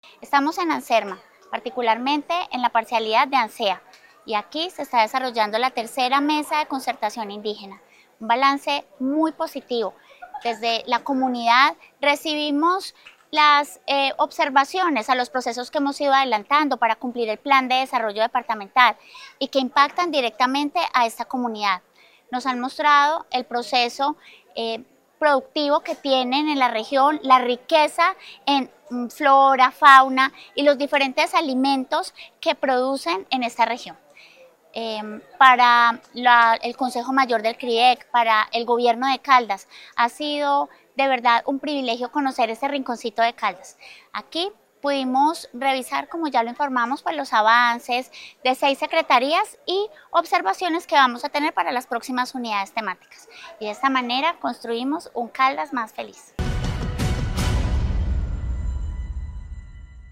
En medio de diálogos constructivos, danzas y músicas propias, así como muestras de la gastronomía de la zona, se llevó a cabo la tercera Mesa de Concertación Indígena de Caldas, realizada en la parcialidad Ansea, del municipio de Anserma.
Sandra Patricia Álvarez Castro, secretaria de Integración y Desarrollo Social.